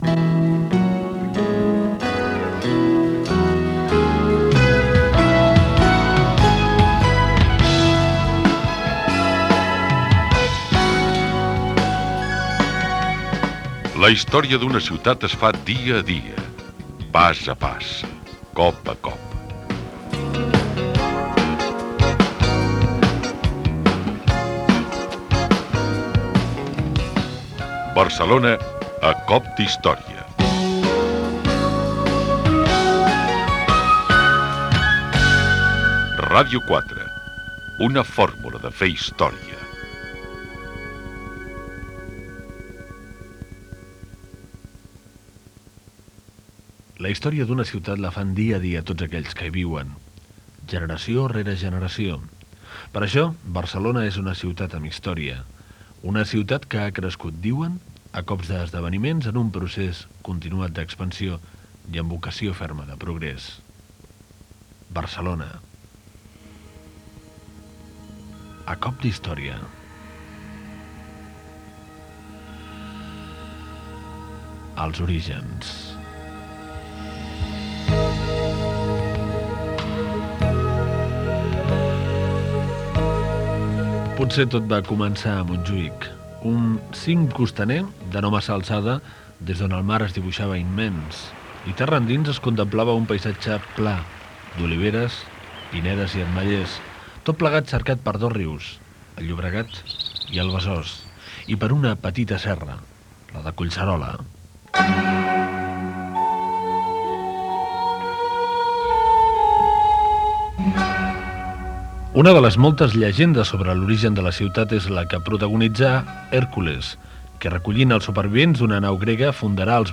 6b8e9b0fd97e28a6e04983e8f4faed04df9b4f9b.mp3 Títol Ràdio 4 Emissora Ràdio 4 Cadena RNE Titularitat Pública estatal Nom programa Barcelona a cop d'història Descripció Capítol 1 "Els orígens". Gènere radiofònic Cultura